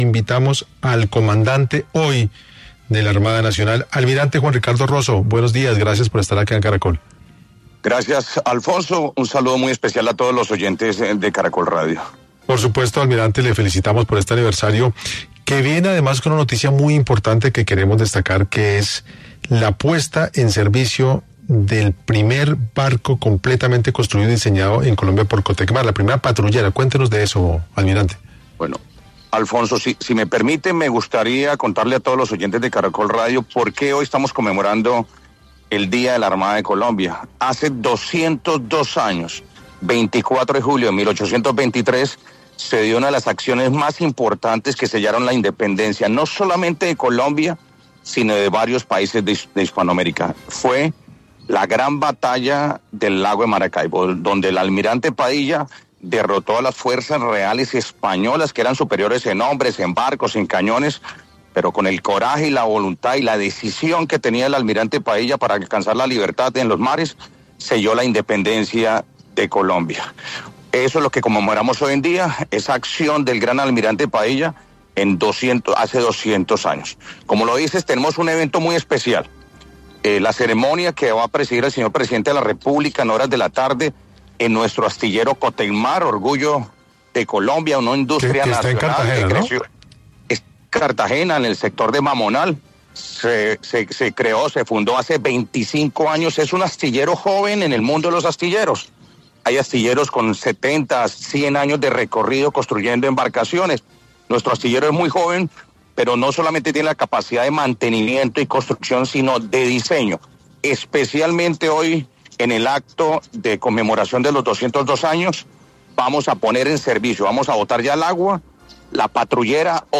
Almirante Juan Ricardo Rozo, comandante Armada de Colombia, se refirió a la presentación del primer buque hospital que beneficiará a más de 110 mil habitantes de los departamentos de Chocó, Valle del Cauca, Cauca y Nariño